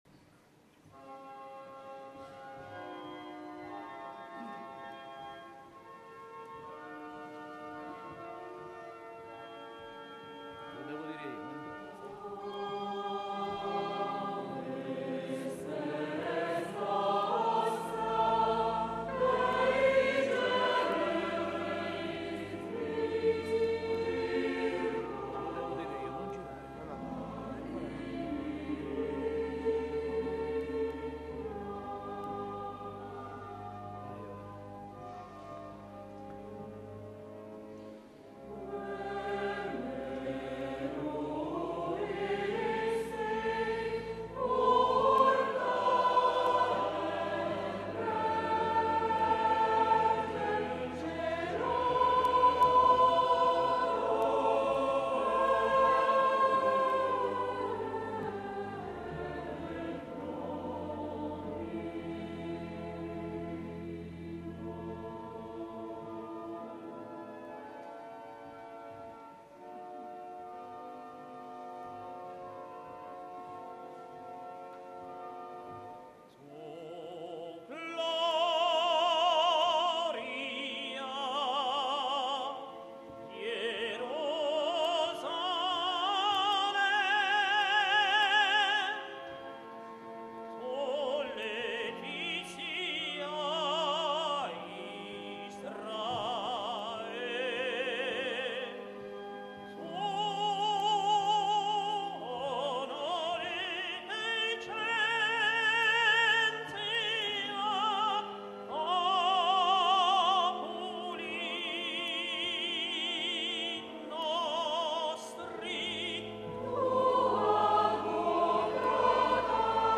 Tenore
Coro: Schola Cantorum Saione
Organo
Registrazione dal vivo 15/2/2003 Arezzo